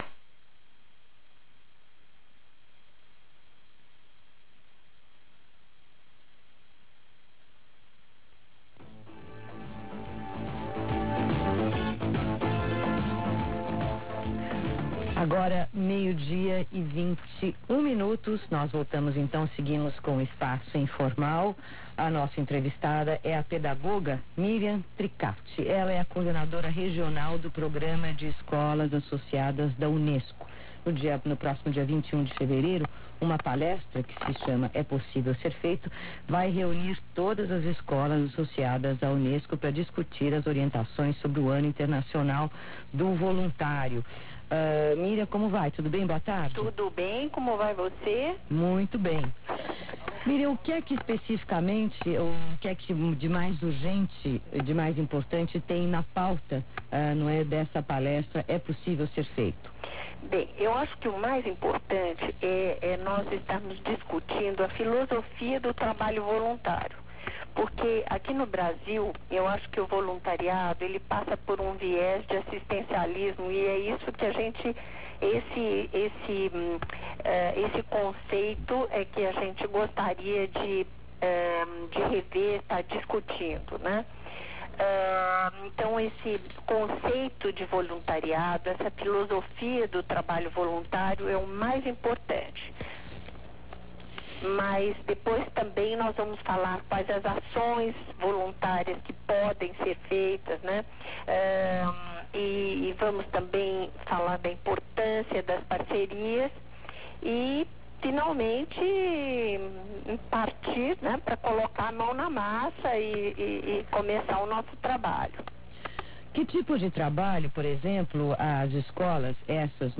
Entrevista sobre o Ano Internacional do Voluntariado / UNESCO